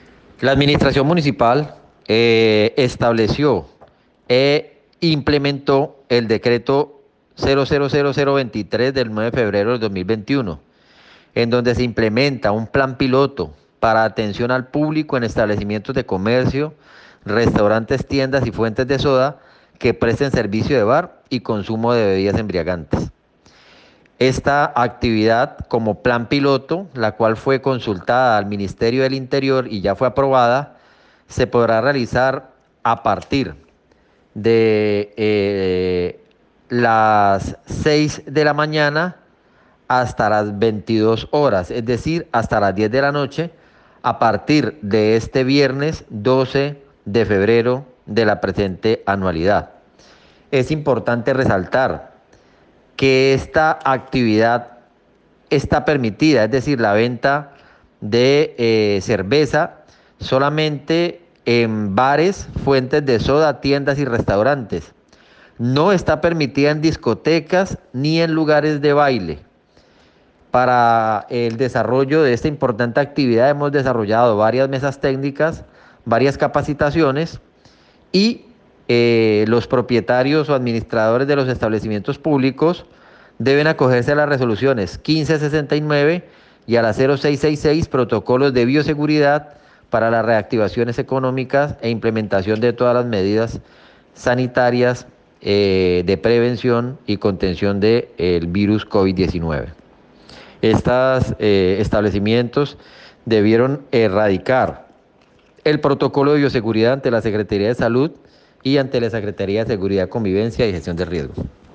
Juan Carlos Pinto, Secretario de Seguridad.mp3